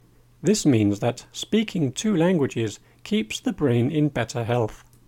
DICTATION 4